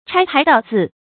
拆牌道字 chāi pái dào zì
拆牌道字发音
成语注音ㄔㄞ ㄆㄞˊ ㄉㄠˋ ㄗㄧˋ